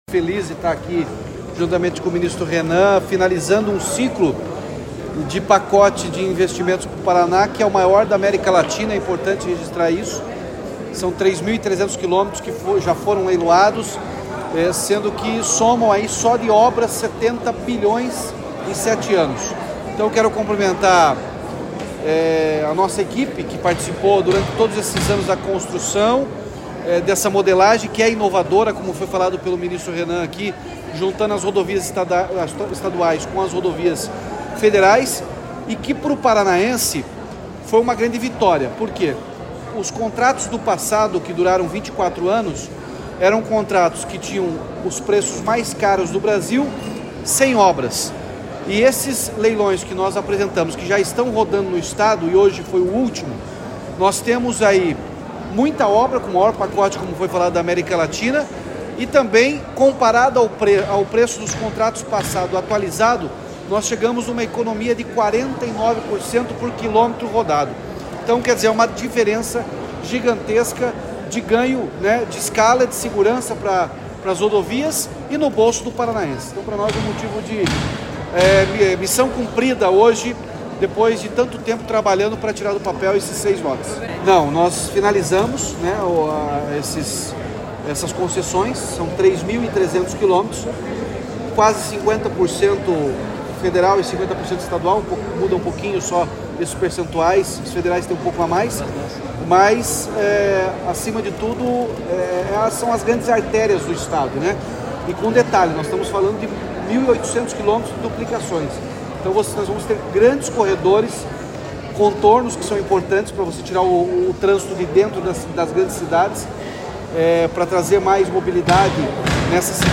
Sonora do governador Ratinho Junior sobre o resultado do leilão do Lote 5 das Rodovias Integradas do Paraná